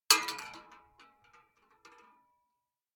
Bullet Shell Sounds
shotgun_metal_7.ogg